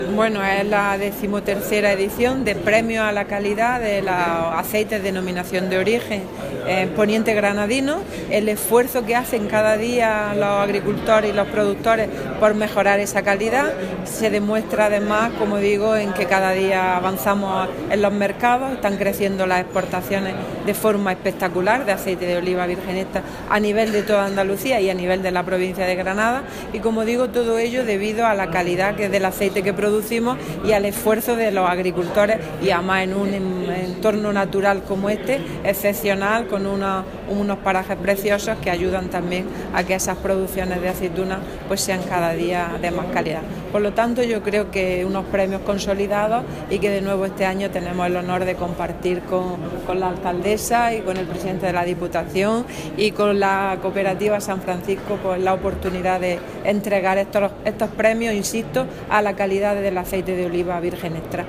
Declaraciones consejera premios